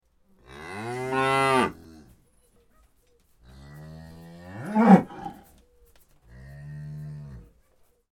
جلوه های صوتی
دانلود صدای گاو در طویله از ساعد نیوز با لینک مستقیم و کیفیت بالا